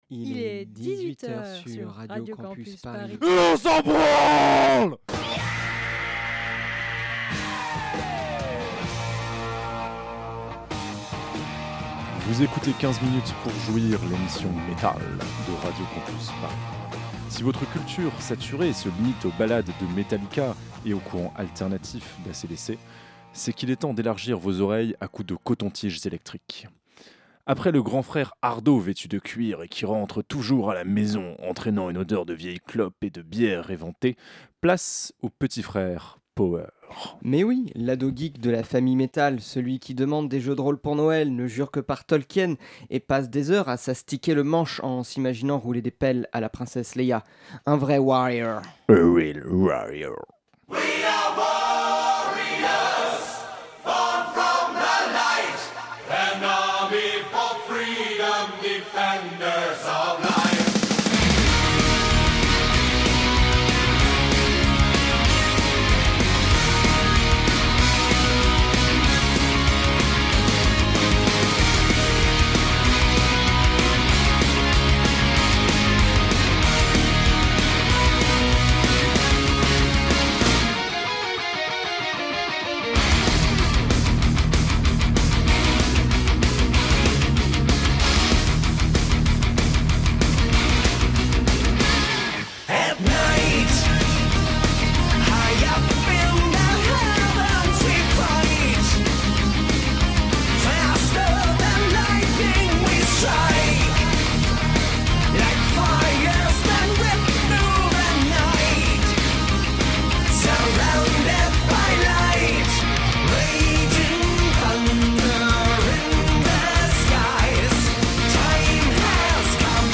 Aujourd'hui, on t'emmène dans un univers de dragons et de princesses, où le fantastique rime avec double-pédale et fantasy avec sonorités épiques. Bienvenue dans les contrées du Power metal, là où ton imagination n'a pour limite que celle que tu lui donnes.